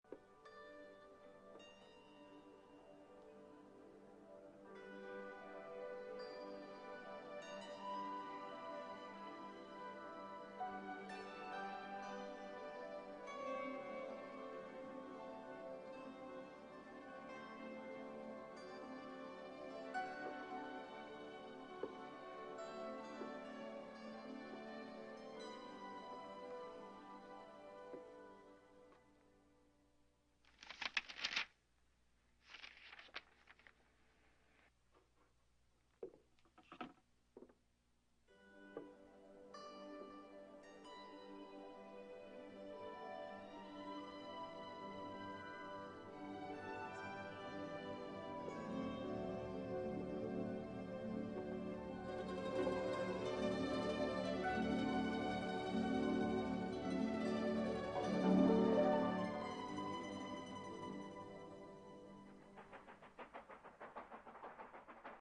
The scene is just a few seconds before Yuri sees Lara the first time. The moments when Lara is close is always accompanied by the sound of the Balalaika.